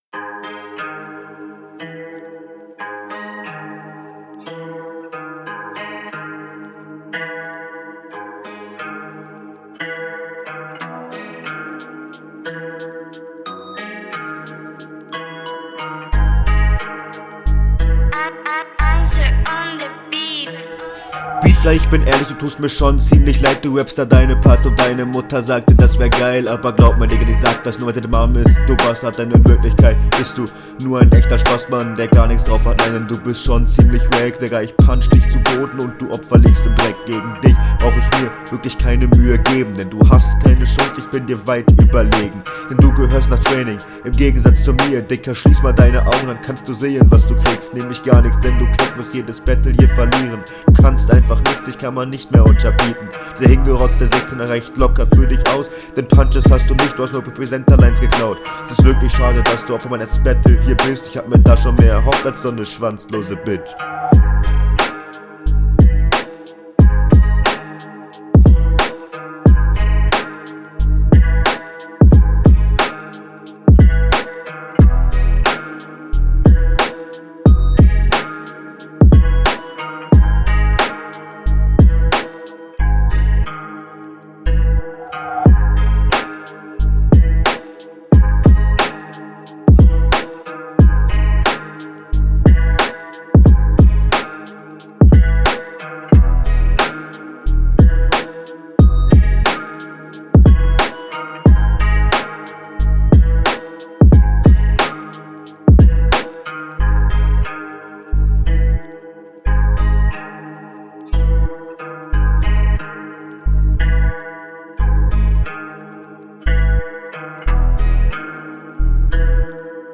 Guter stabiler flow und Kürze Mal die runden weil ich dachte das wirklich ne 3min …
Hmmm cooler Beat. Allerdings nutz du die möglichkeiten die der Beat bietet kaum aus. Stimmlich …